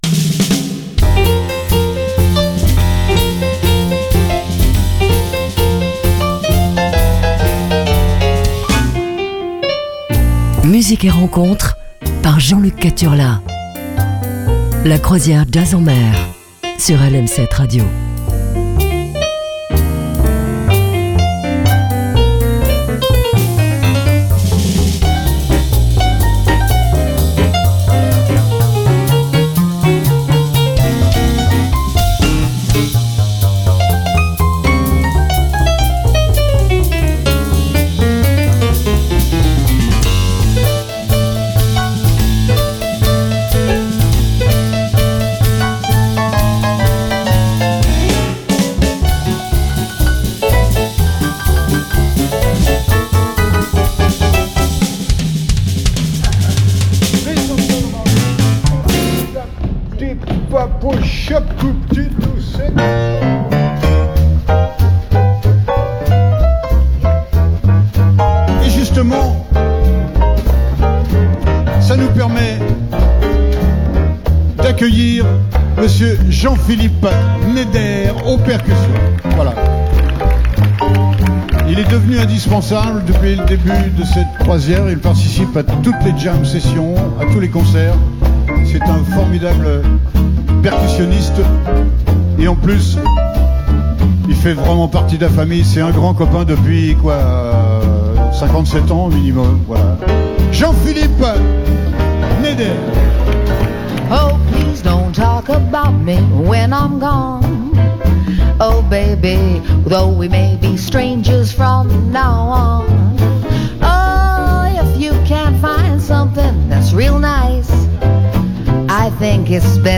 rythmes jazzy